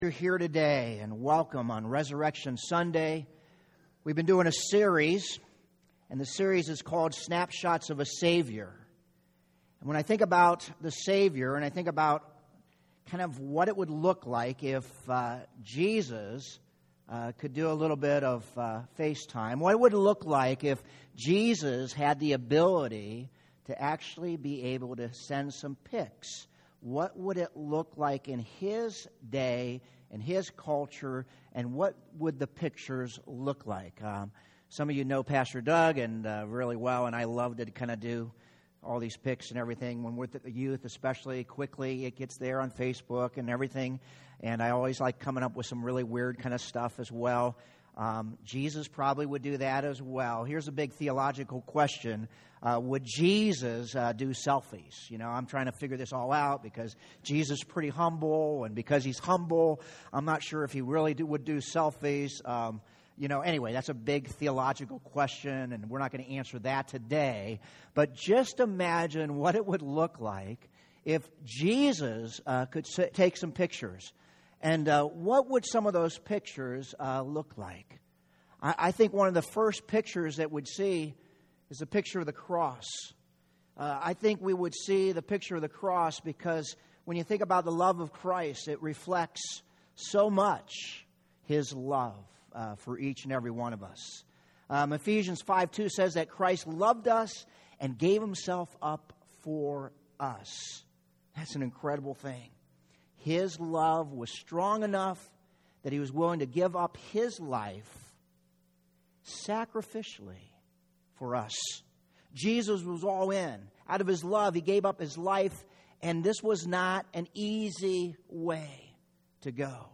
Medina Community Church Sermons
Easter Sunday 4-16-17 A.mp3